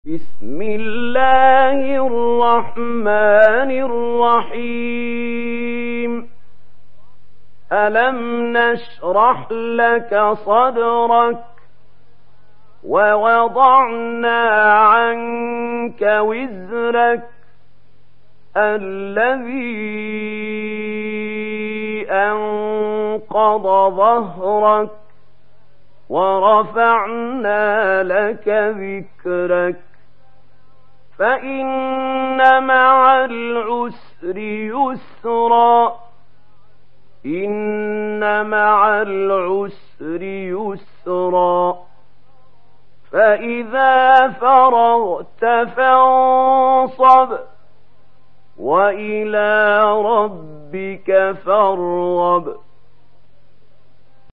تحميل سورة الشرح mp3 محمود خليل الحصري (رواية ورش)